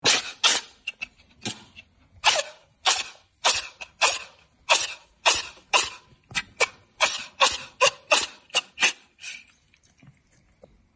Sick Cat Sneezing - Realistic Pet Bouton sonore